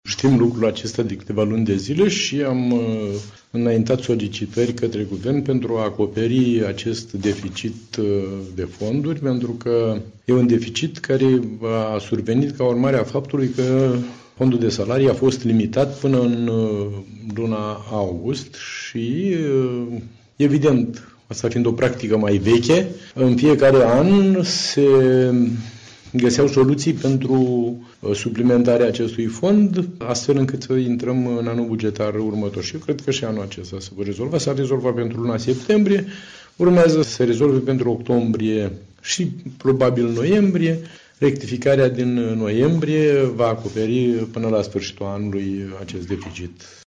Dumitru Buzatu, presedintele CJ Vaslui, a declarat ca spera ca la o viitoare sedinta de Guvern sa fie alocati bani pentru Direcția pentru Protecția Copilului.